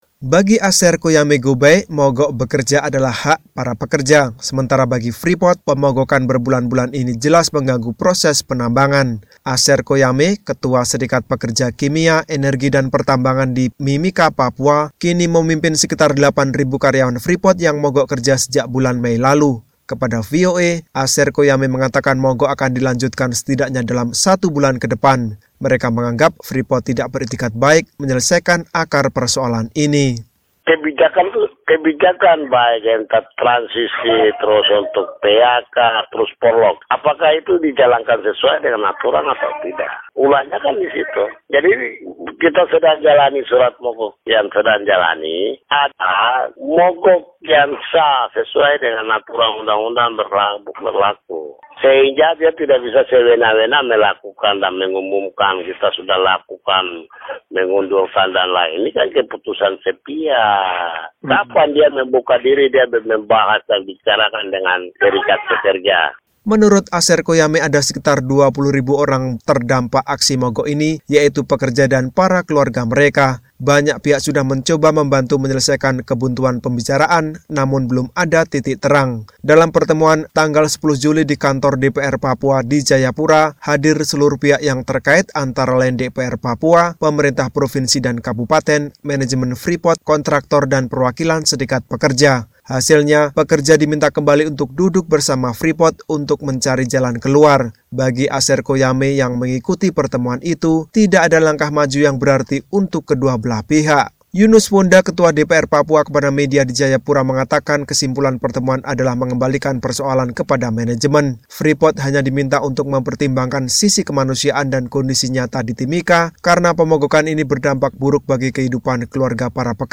Keputusan itu berbuah pemogokan ribuan pekerja sejak Mei. Berikut laporan selengkapnya.